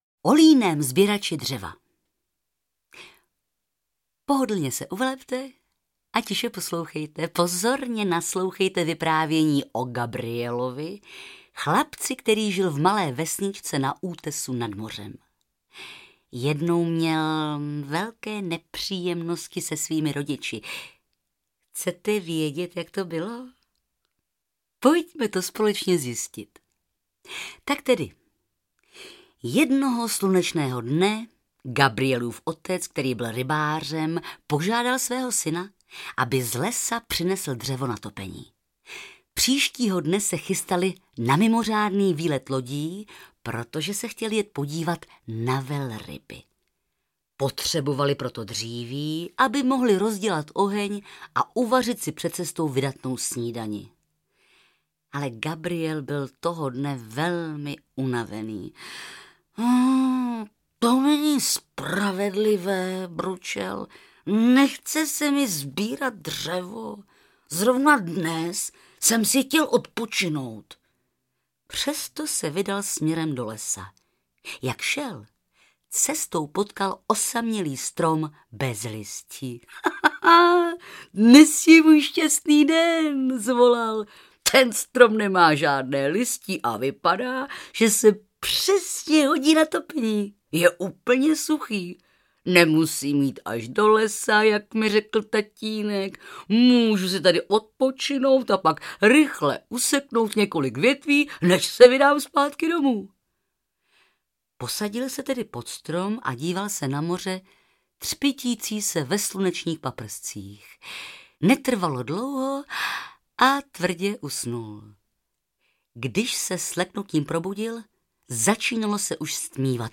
Ukázka z knihy
Barbora Hrzánová čte pohádky zasvěceně a s netajeným potěšením.
• InterpretBarbora Hrzánová